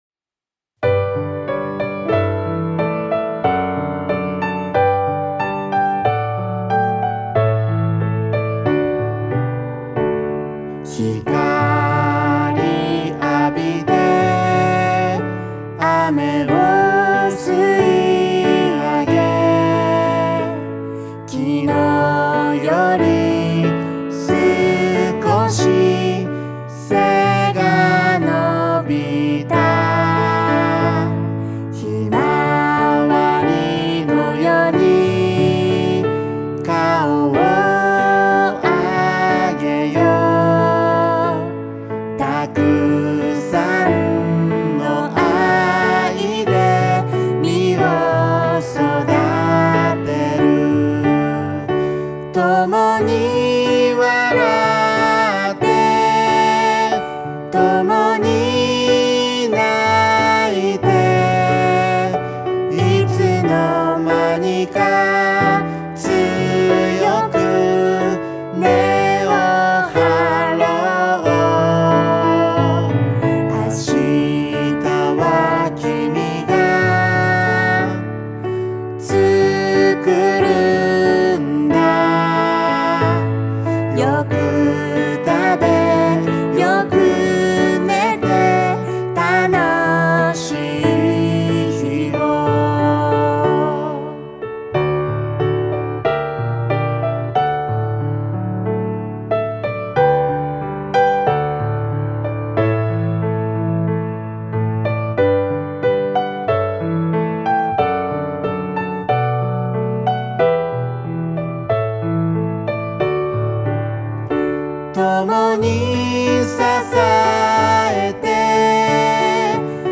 > Piano Arrangement
ACOUSTIC / CLASSIC